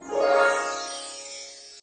magic_harp_2.ogg